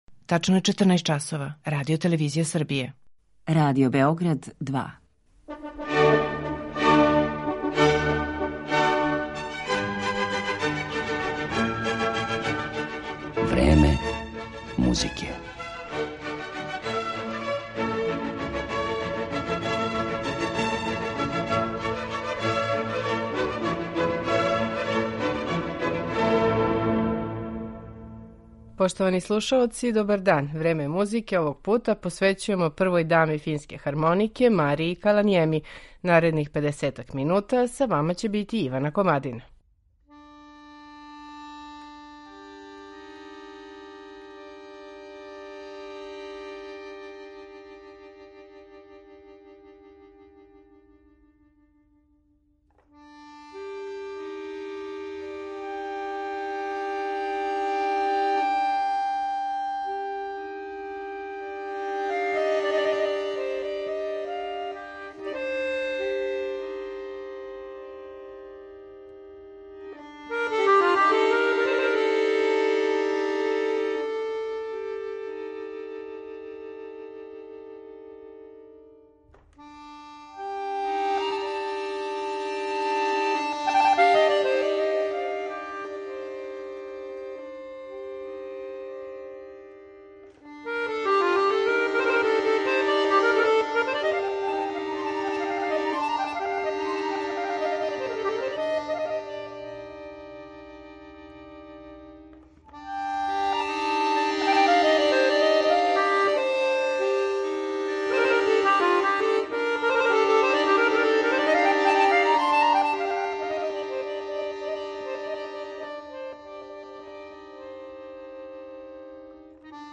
Особени стил Марије Каланиеми, прве даме финске хармонике, збуњује музичке критичаре: у фолклором инспирисане мелодије увела је много елемената попа, џеза и уметничке музике и створила нешто што је веома тешко категоризовати, а што се обично квалификује као Нова музичка традиција.